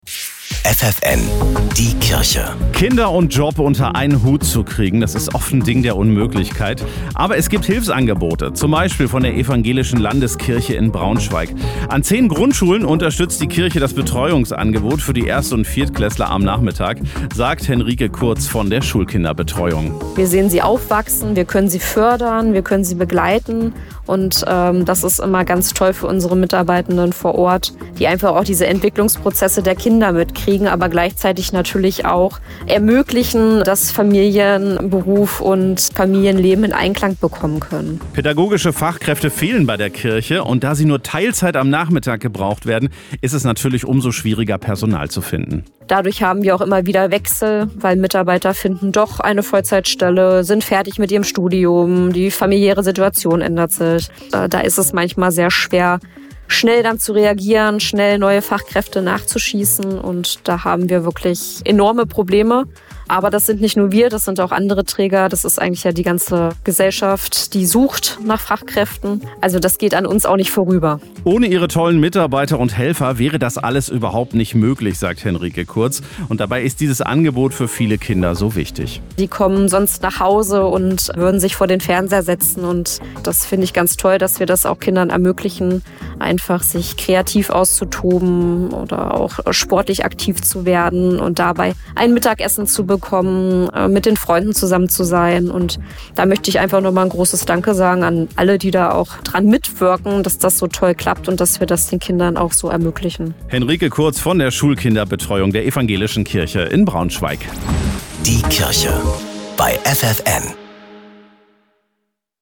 Die Schulkindbetreuung im Radio.
Propstei, im Interview